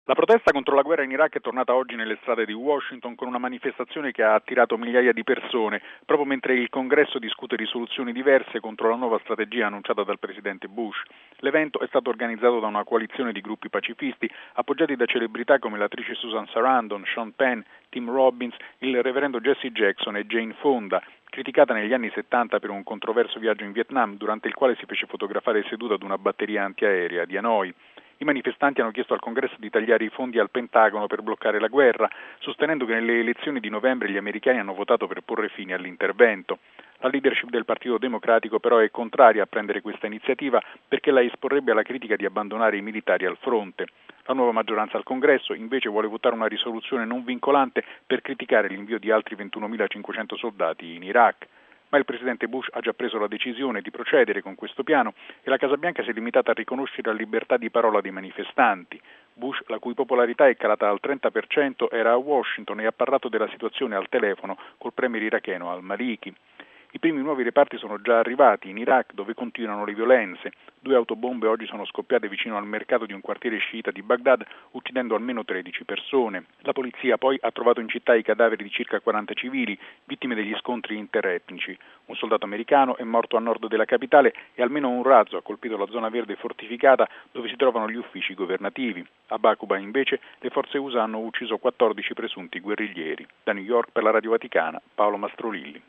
(27 gennaio 2007 -RV) Ancora una mattinata di sangue a Baghdad, una decina di persone sono morte a seguito di un duplice attentato suicida mentre altre otto sono state sequestrate da un commando armato. La situazione fuori controllo in Iraq, le fortissime perdite americane in questa guerra, assieme ad altre questioni interne, hanno determinato, secondo Newsweek, il crollo della popolarità del presidente Bush. Da New York